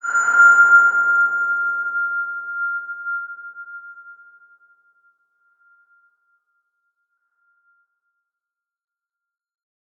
X_BasicBells-F4-mf.wav